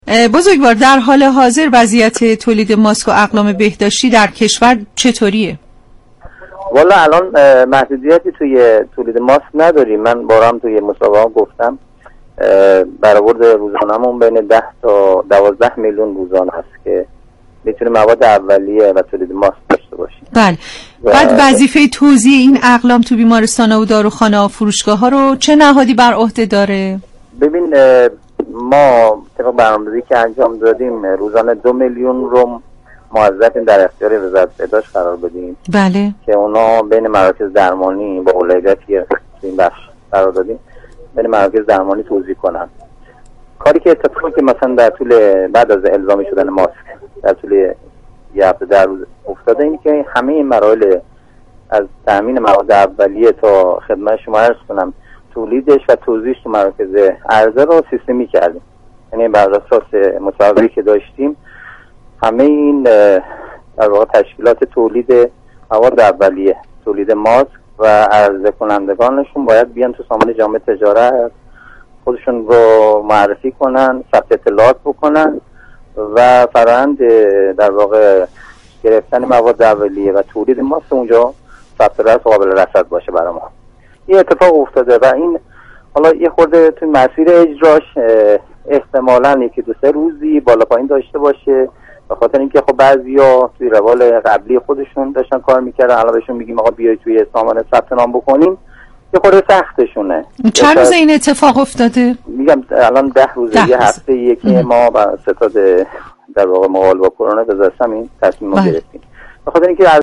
با توجه به شیوع ویروس كرونا و الزام ستادملی مبارزه با كرونا مبنی بر استفاده همگانی از ماسك، برنامه دال رادیوجوان در گفتگو با یكی از مسئولان وزارت صمت، پیگیر تولید و توزیع ماسك در كل كشور شد.